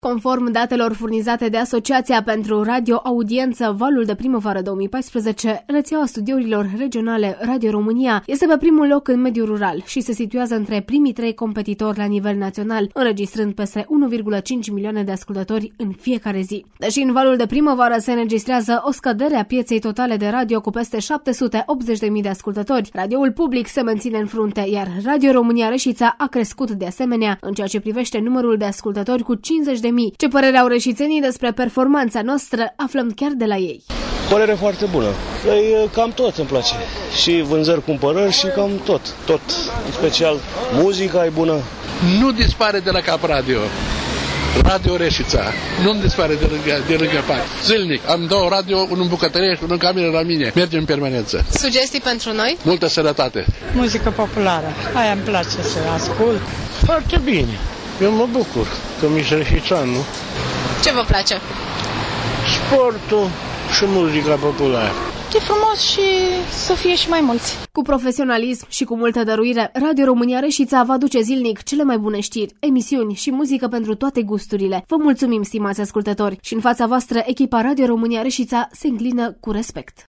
Ce părere au reşiţenii despre performanţa noastră aflăm chiar de la ei.